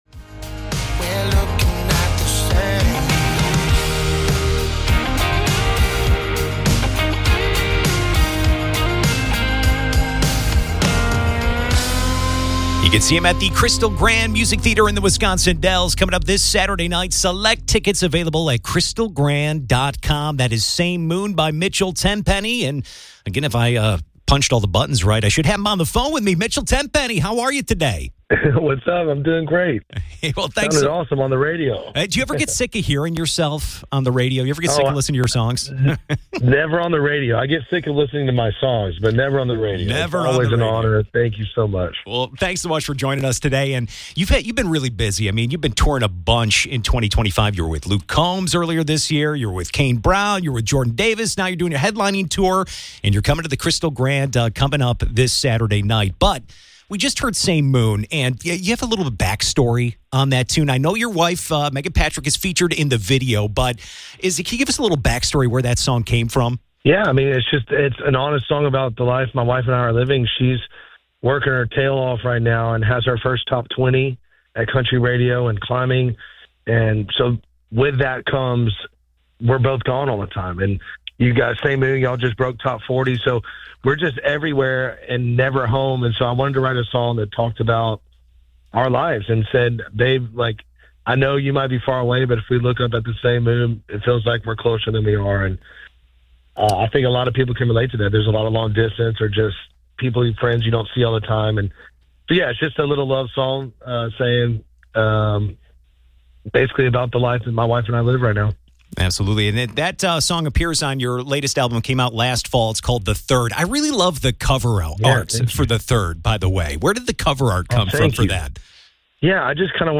Mitchell Tenpenny Interview